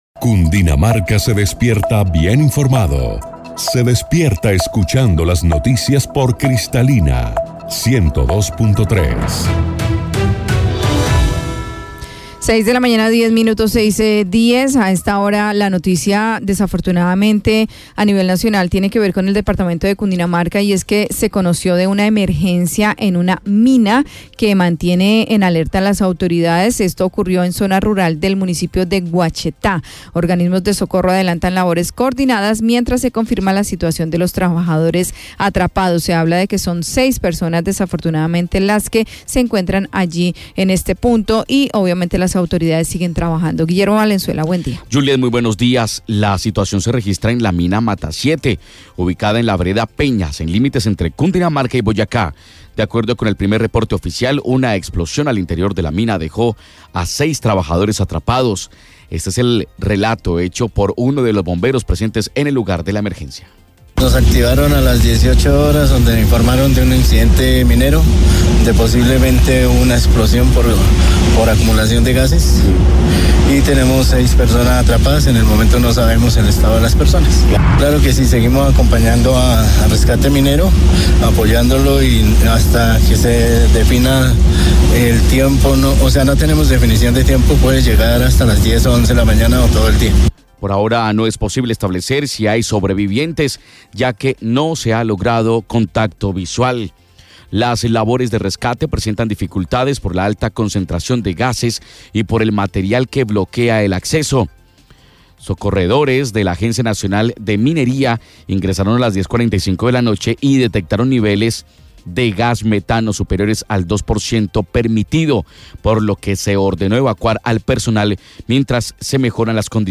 AUDIO_NOTICIA_MINEROS.mp3